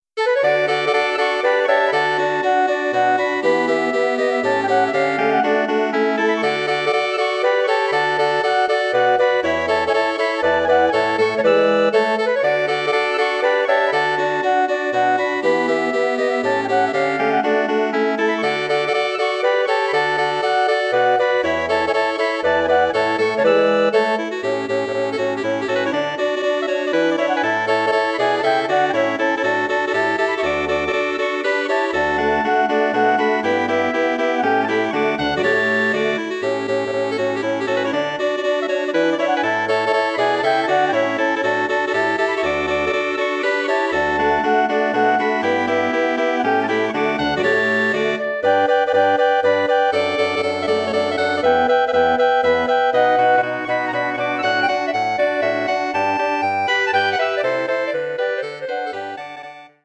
Bearbeitung für Bläserquintett
Besetzung: Flöte, Oboe, Klarinette, Horn, Fagott
Arrangement for wind quintet
Instrumentation: flute, oboe, clarinet, horn, bassoon